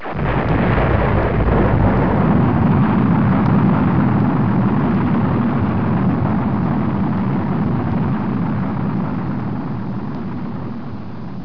rocket1.mp3